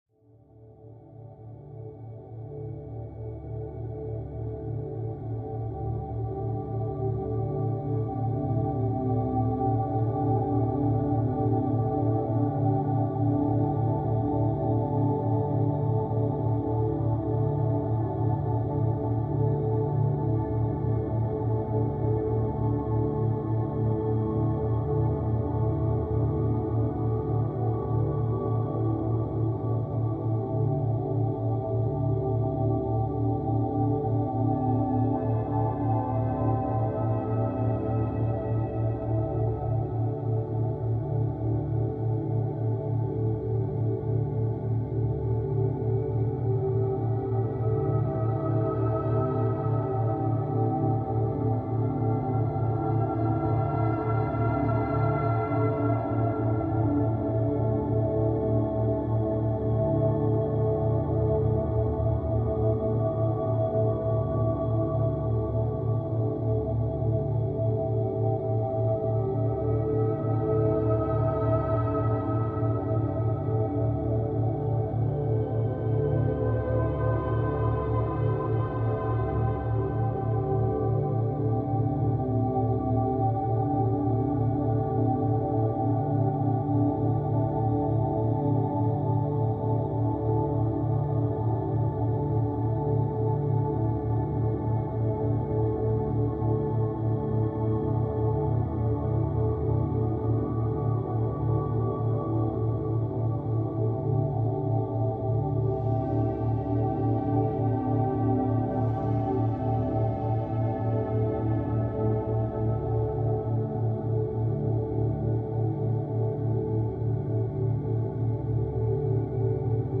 Es beginnt leise.